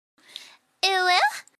Download Uwu sound effect for free.